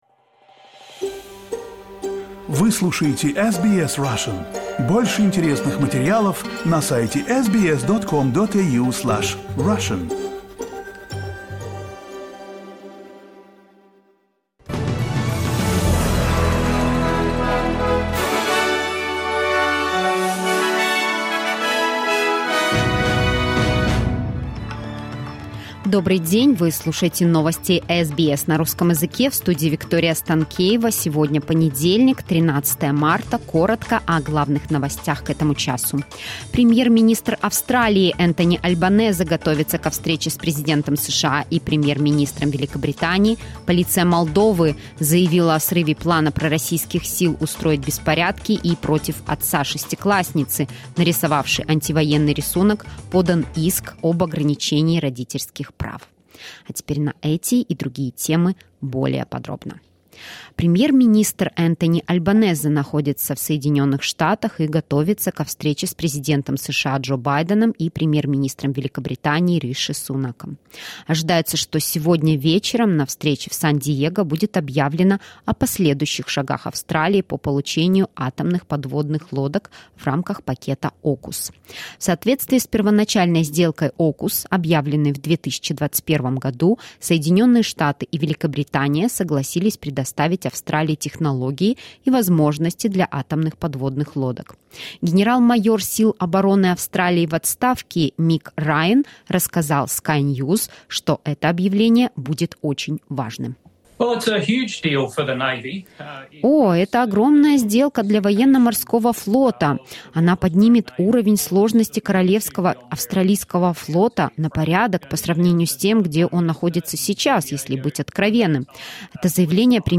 SBS news in Russian — 13.03.2023